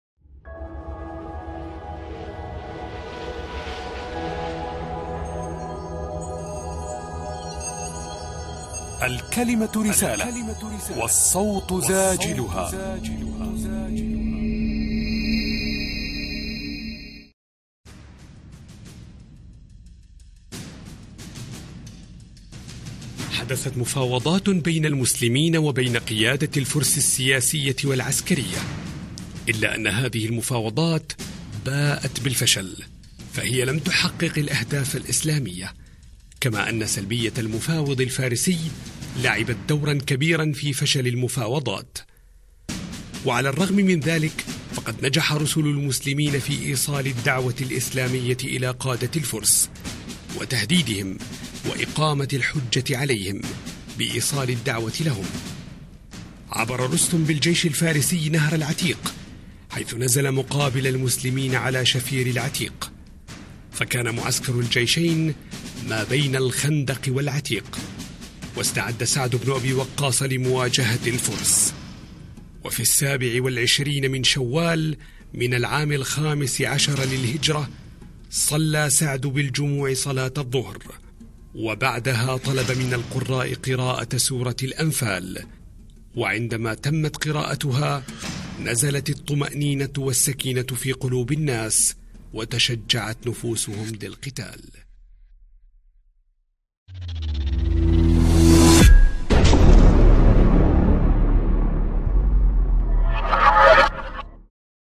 التعليق الصوتي Voice over
اللون الوثائقي
يتميز بالتصويري الذي ينقل حدثاً معيناً بما يرافقه من متطلبات نفسية مرافقة كالتوتر والخوف والثقة وذلك بما يفرضه النص.